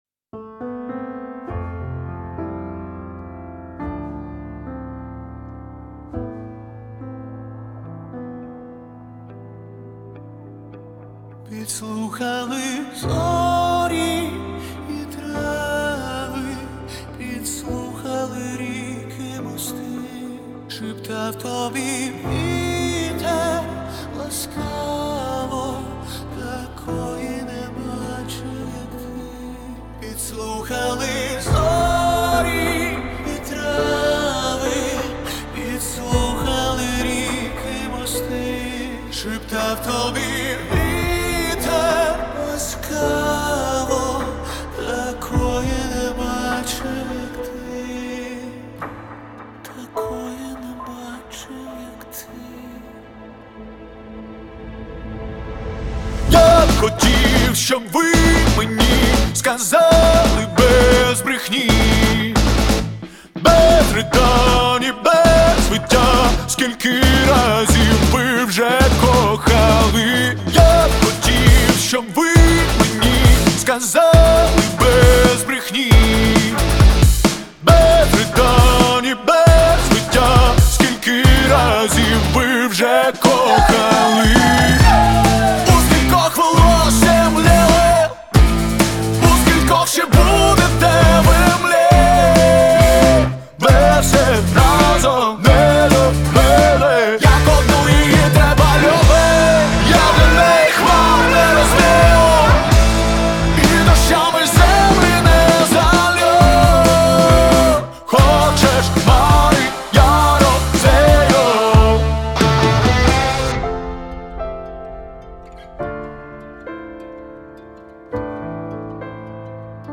• Жанр: Українські пісні